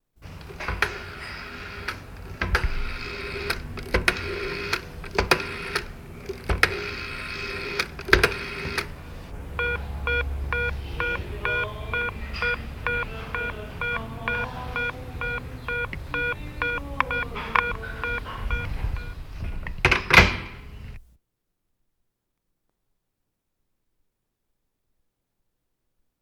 Звук дискового телефона с крутилкой из советских времен